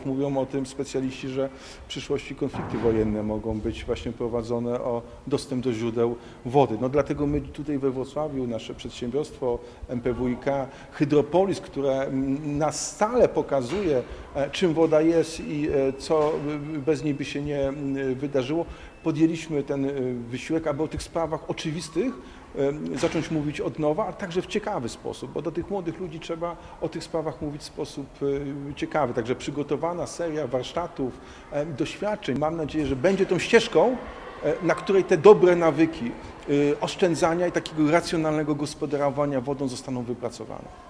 Woda jest wszędzie, ale nie jest jej dużo, dlatego mówimy o tym, żeby oszczędzać wodę – mówił do uczniów Szkoły Podstawowej nr 67 przy pl. Muzealnym 20 Jacek Sutryk, prezydent Wrocławia.